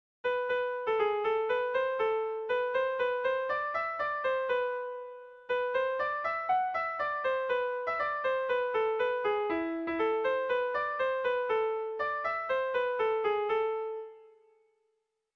Neurrian badu gorabeherarik, 3.puntuan behintzat.
ABDE